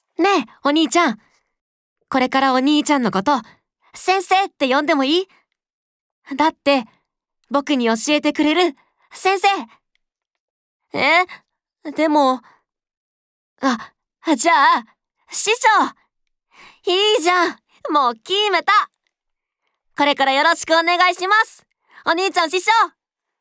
ボイスサンプル
少年